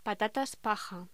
Locución: Patatas paja
voz